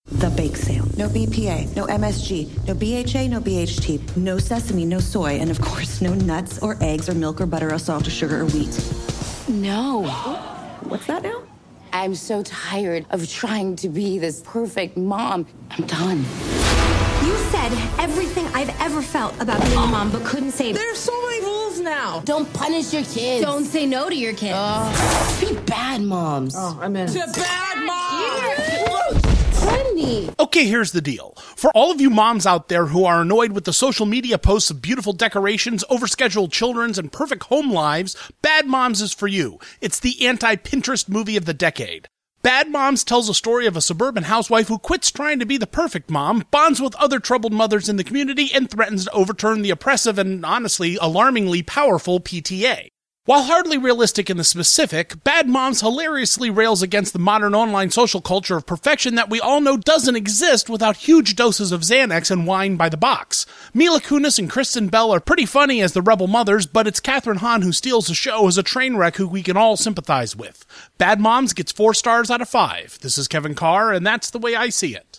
‘Bad Moms’ Radio Review